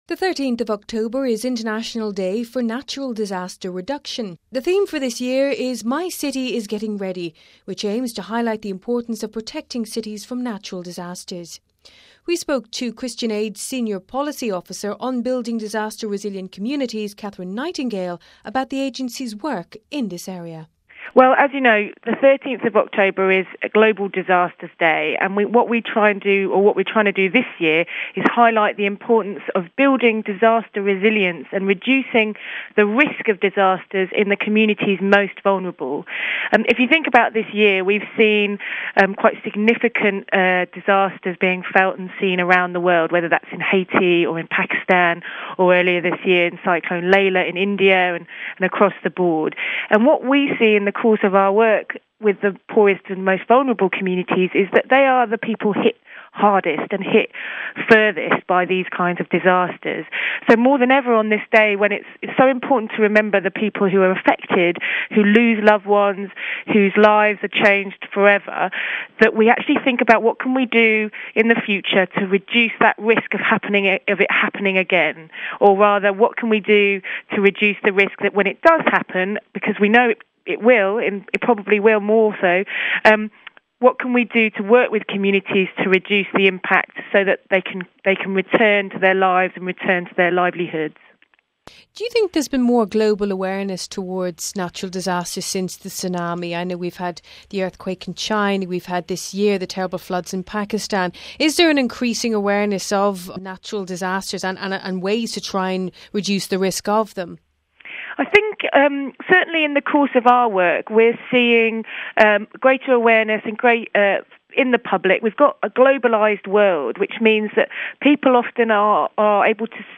Speaking to Vatican Radio